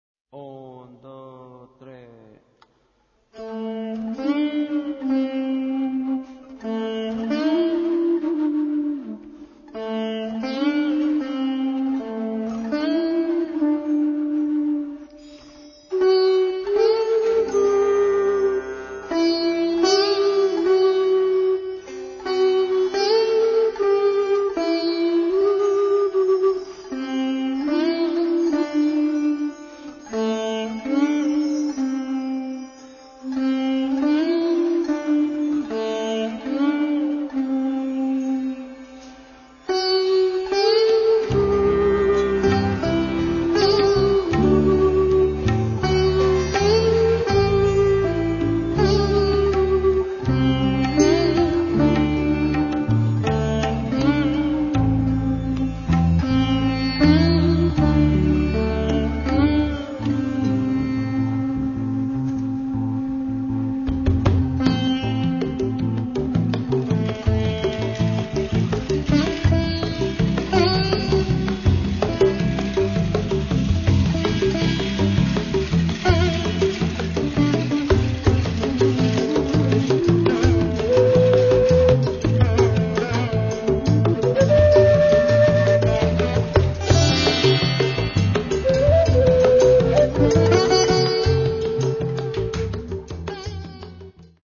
electroacoustic & electric bass, guitar
saxophones, bass clarinet, wooden flute
sitar, oud, guitars
percussions, tanpura
Registrato a Bari